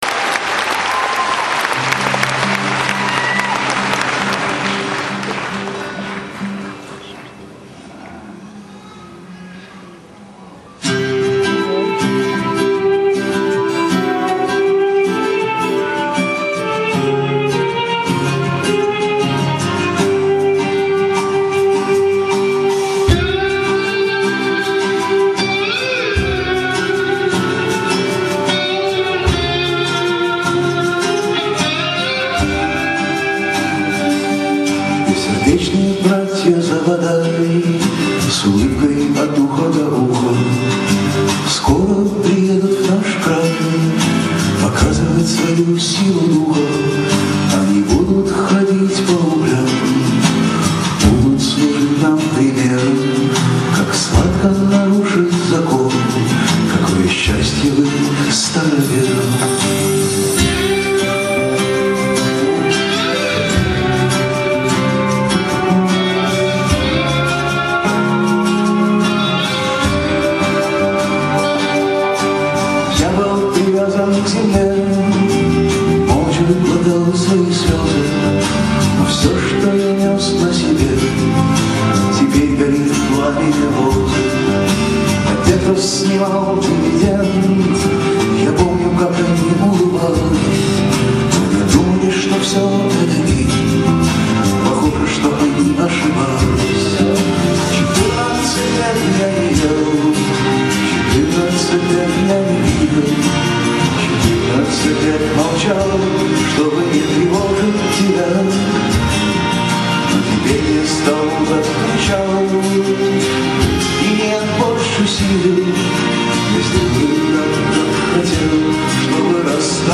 4. 2004-BKZ_Oktyabrskiy